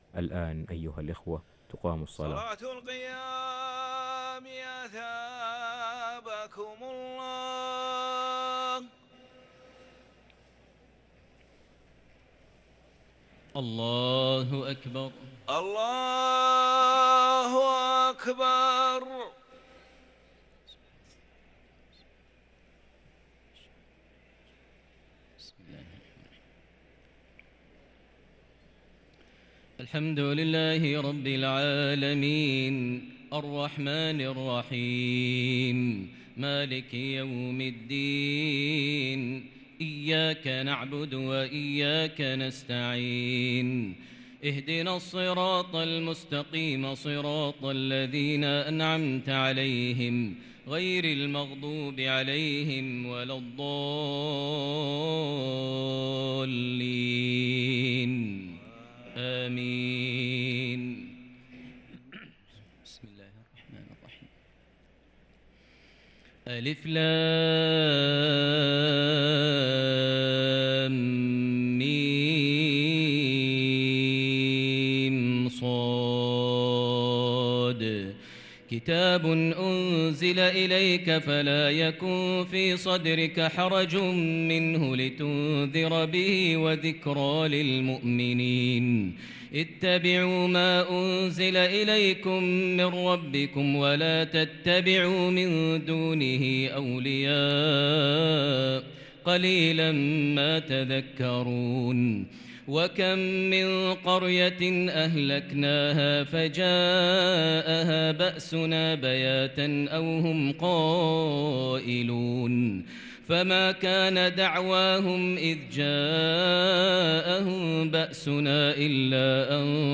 صلاة التراويح ليلة 11 رمضان 1443 للقارئ ماهر المعيقلي - الثلاث التسليمات الأولى صلاة التراويح